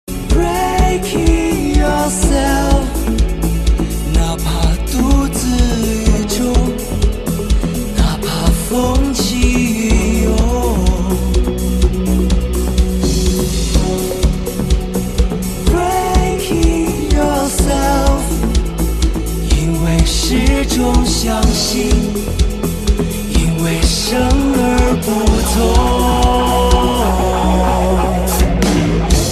M4R铃声, MP3铃声, 华语歌曲 130 首发日期：2018-05-15 14:21 星期二